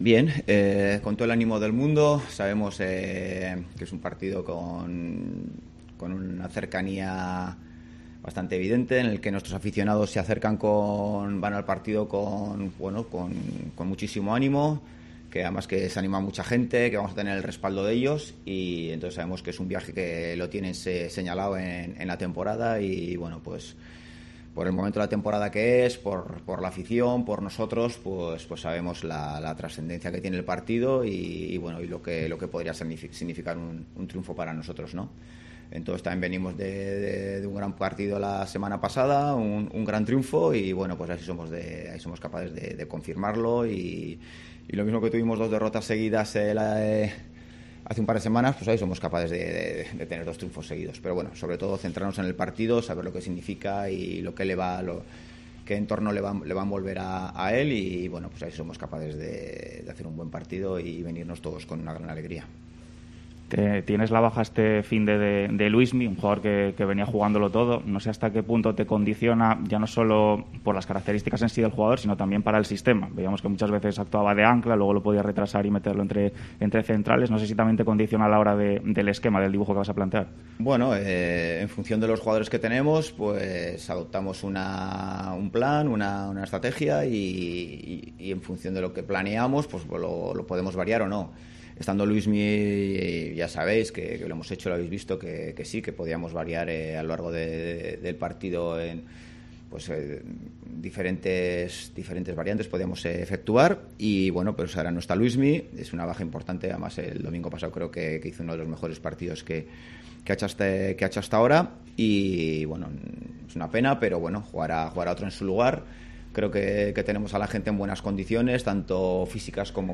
Rueda de prensa Ziganda (previa Lugo)